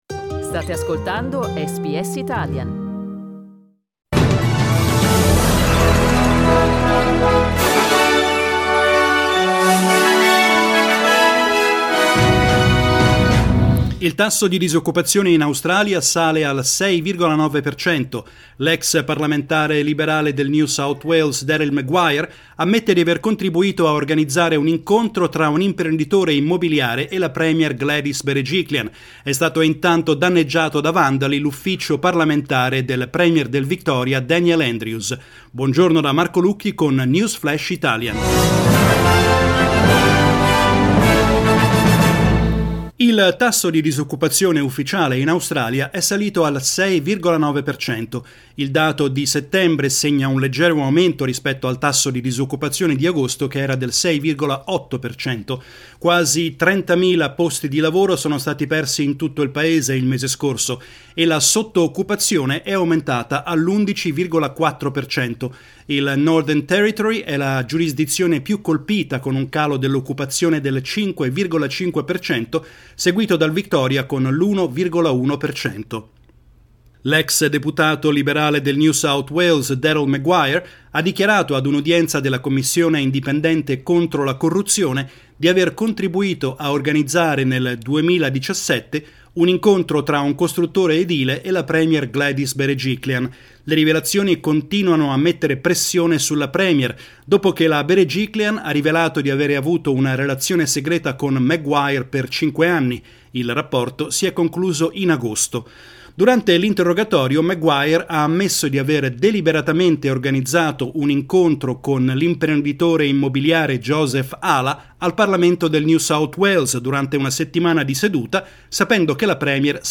Our news update in Italian